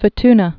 (fə-tnə, f-) also Hoorn Islands (hôrn)